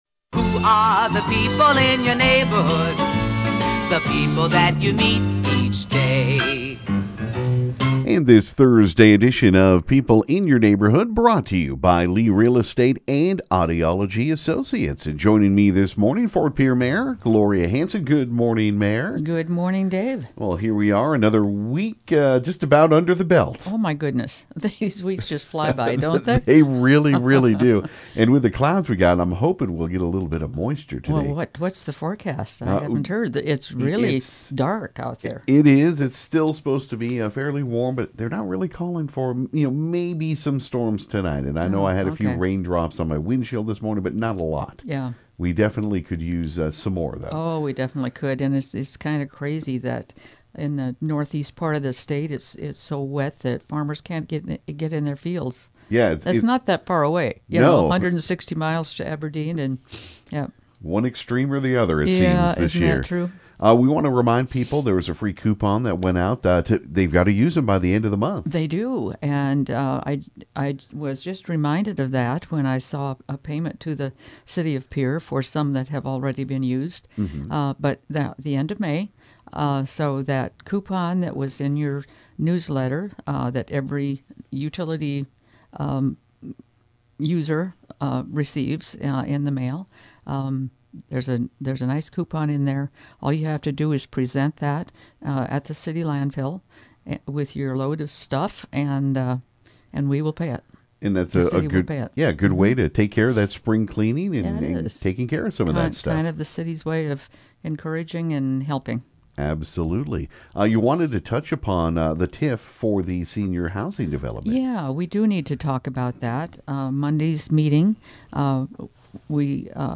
She also gave an update on where the Yellowstone Senior Housing Development is along with touching on the First Thursday events coming up in Fischers Lilly Park, the National Indian Relays which will be in Ft. Pierre and she also mentioned some summer job opportunities.